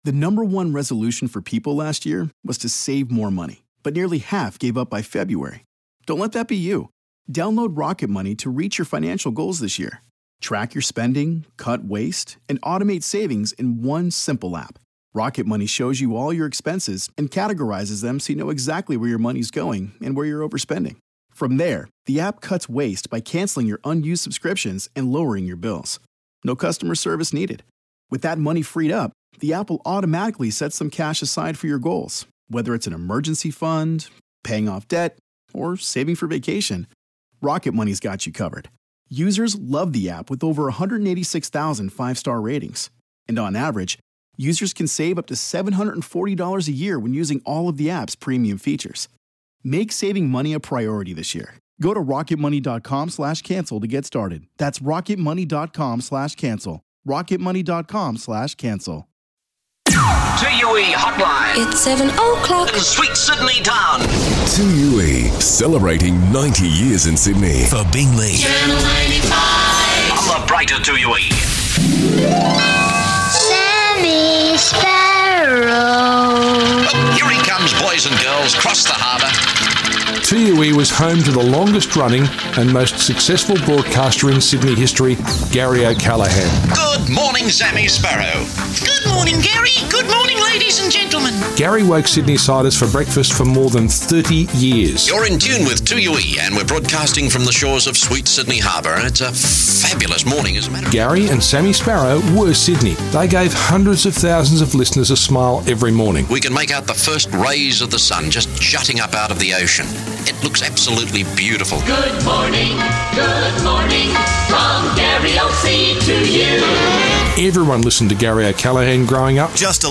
Take a listen back to some classic 2UE flashbacks on 90 years of broadcasting to Sydney and around the globe.